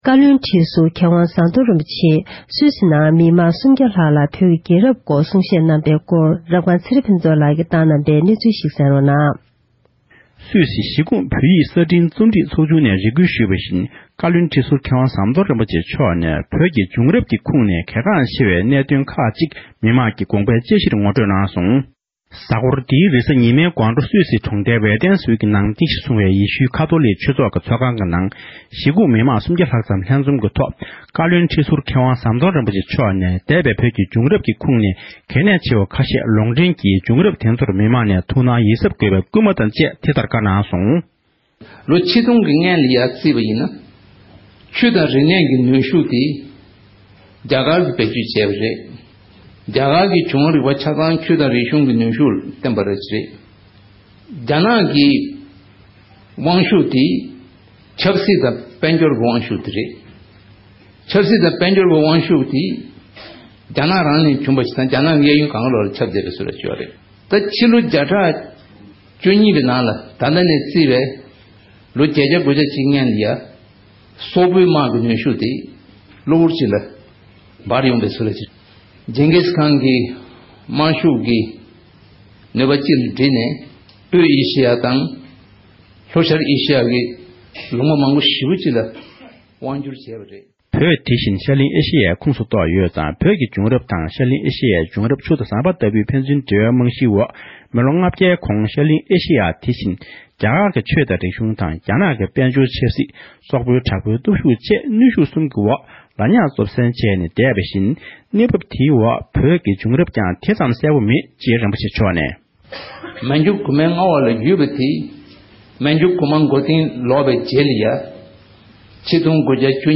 བོད་ཀྱི་བྱུང་རབས་སྐོར་གྱི་གསུང་བཤད།